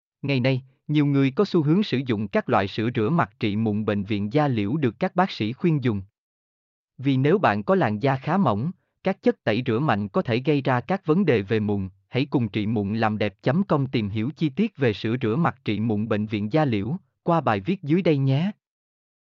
mp3-output-ttsfreedotcom-32-1.mp3